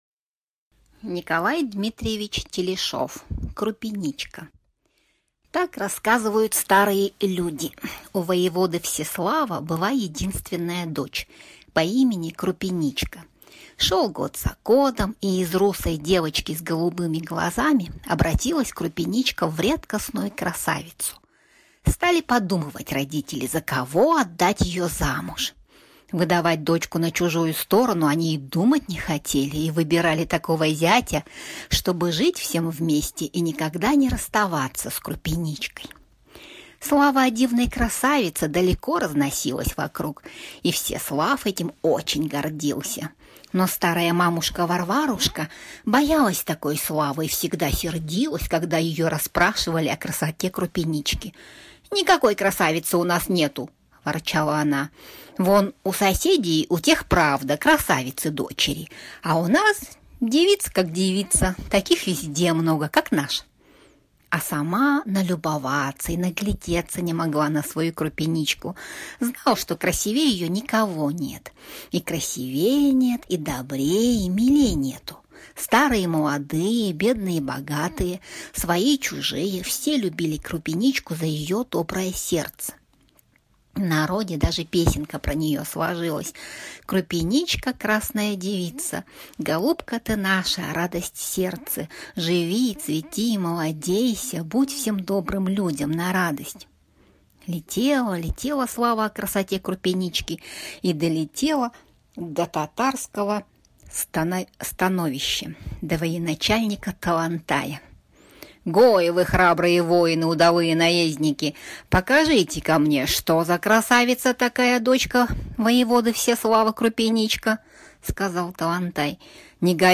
Крупеничка - аудиосказка Телешова Н.Д. Сказка про красивую девушку Крупеничку, которую татары украсть ее и подарить своему хану в жены.